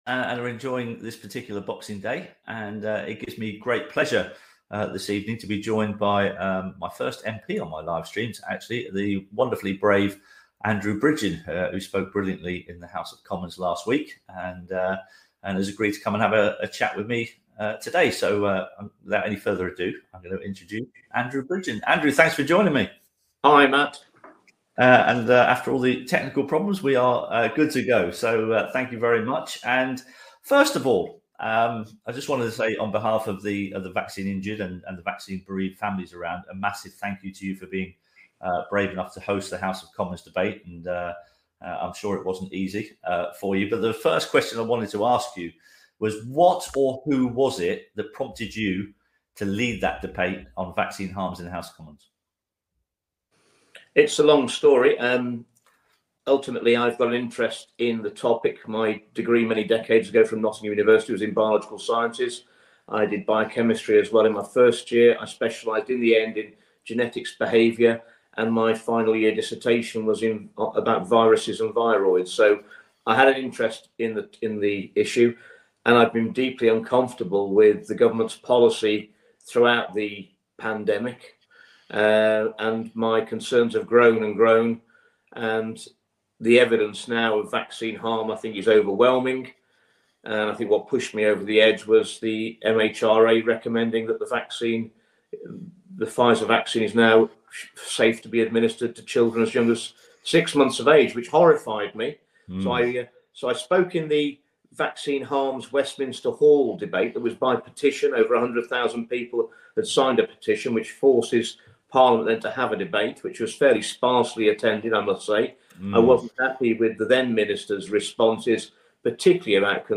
interviews Andrew Bridgen MP